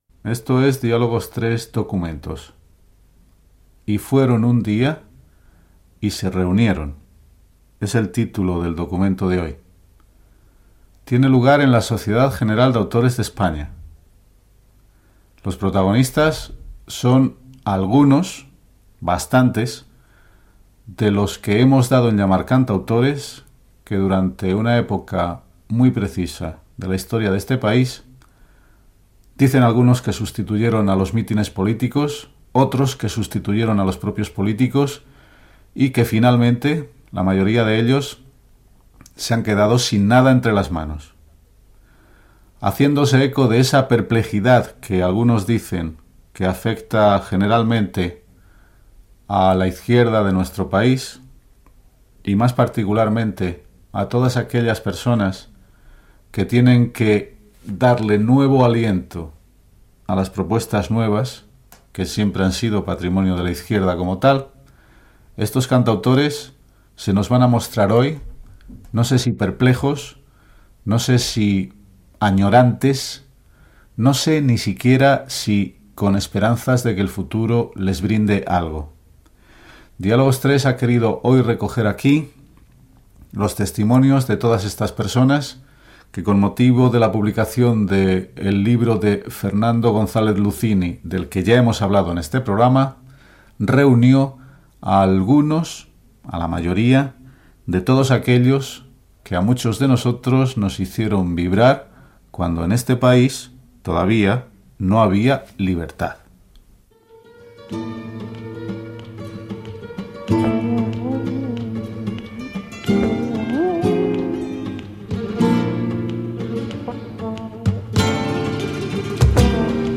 Identificació del programa, presentació d'un espai dedicat als cantautors. Declaracions de l'escriptor Gabriel Celaya i tema musical